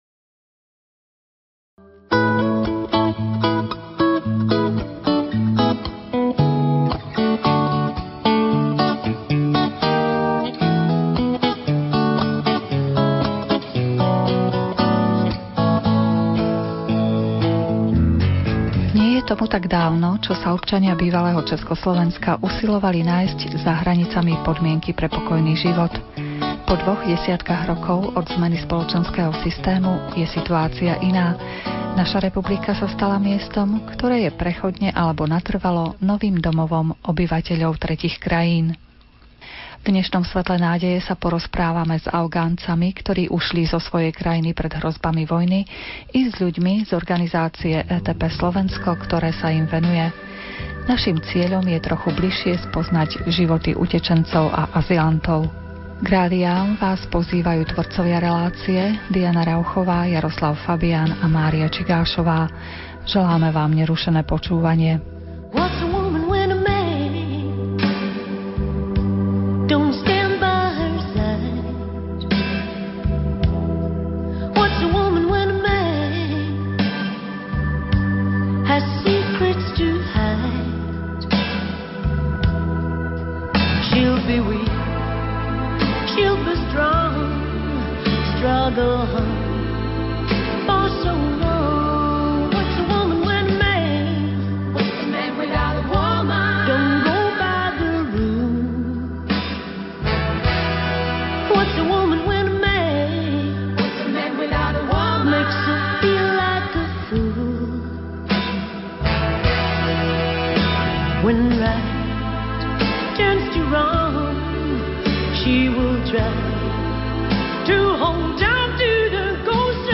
rozhovor.mp3